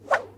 swish_1.wav